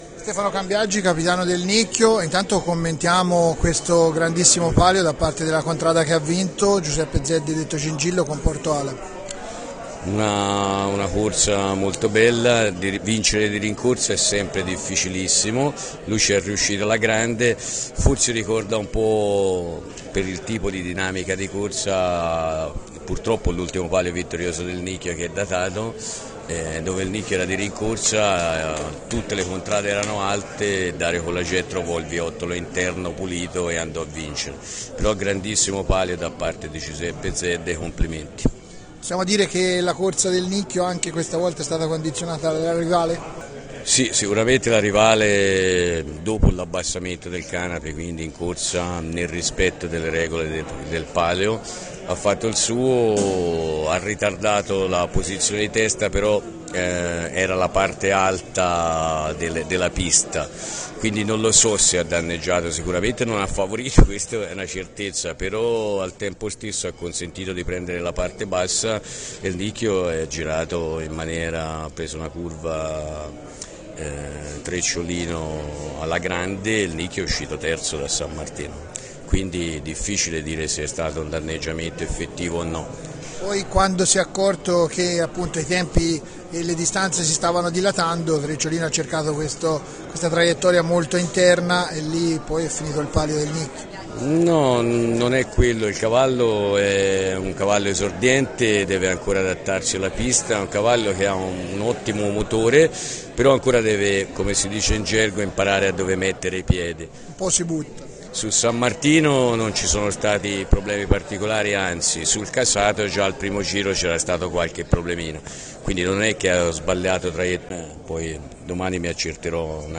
Interviste
Come di consueto, dopo la carriera, abbiamo raccolto i commenti dei capitani delle contrade che hanno partecipato al palio del 16 agosto.